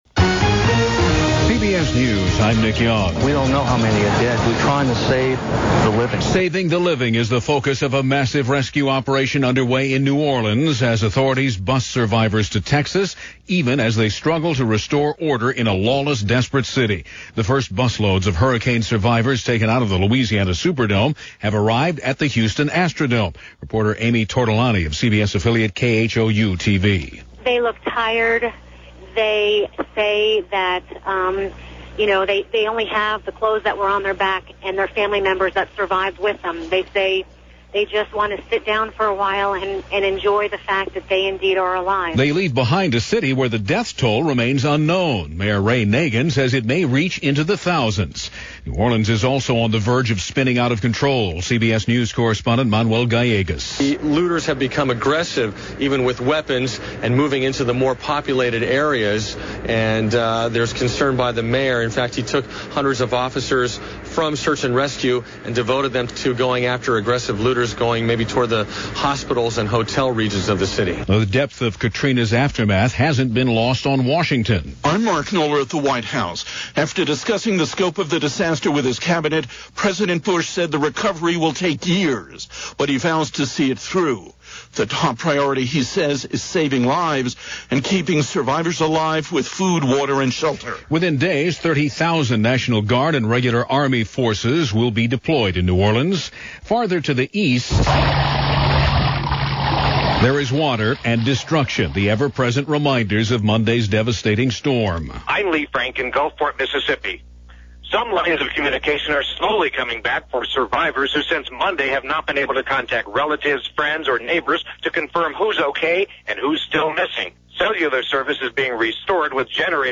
And that’s a small slice of what happened this day, as news continued to unfold from New Orleans, this September 1, 2005 as reported by CBS Radio News via WTOP Newsradio in Washington D.C.